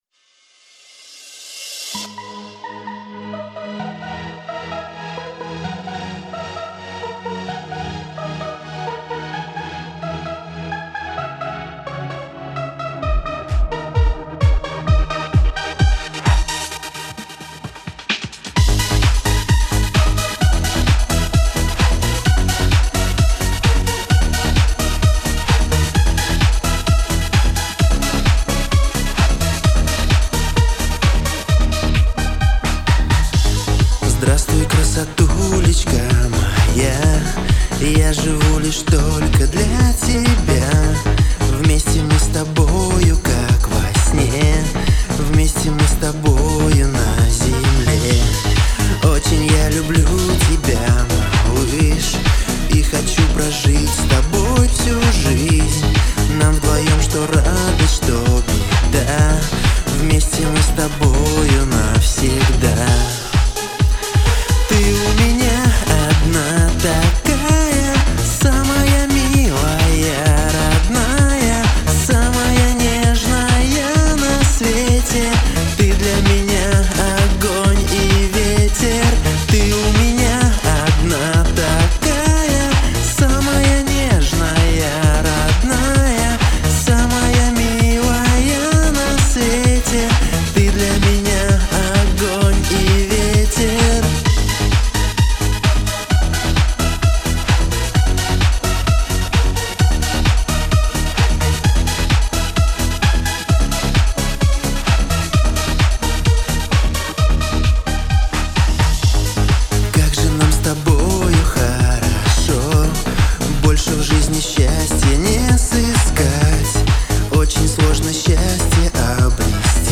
более гармонично звучит от  начала и до конца